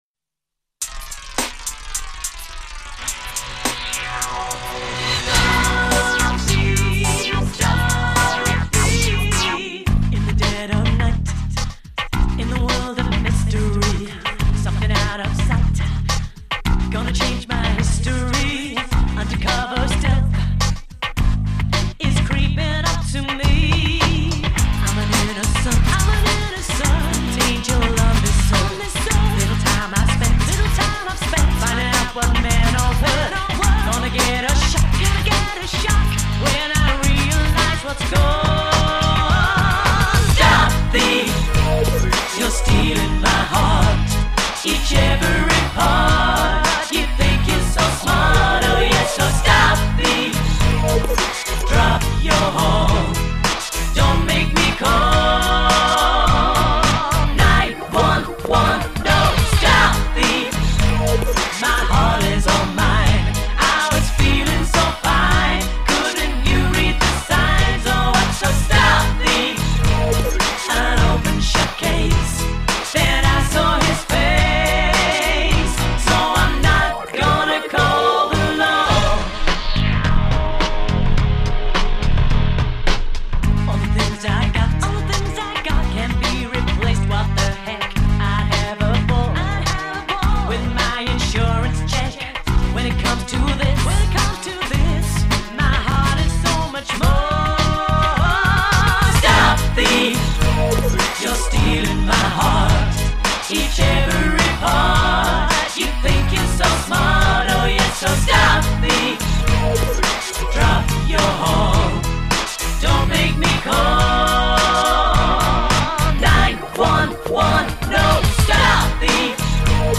Just found this demo that I must have done at the same time as "Barbie", as it's the same track but with lyrics 20% less mawkish.
And yes, this HAS the evil Sonovox repeat call nonsense.